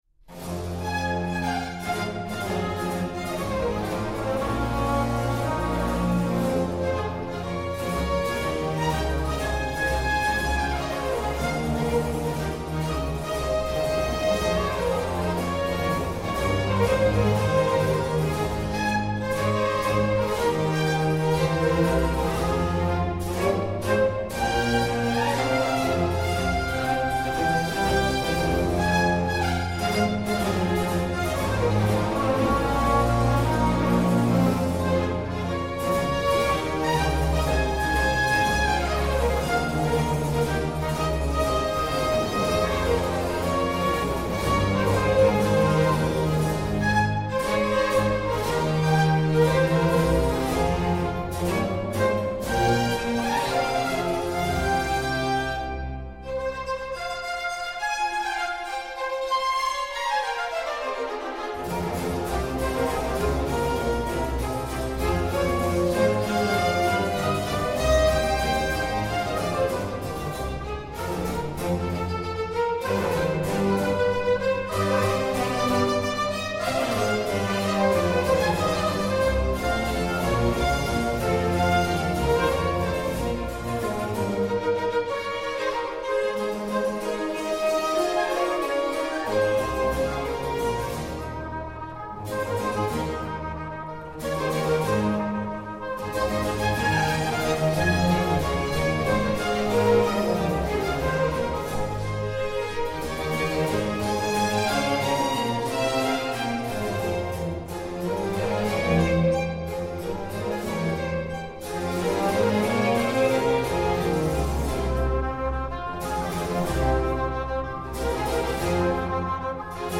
Le Concert Spirituel a Versailles con le sue irresistibili peripezie donchisciottesche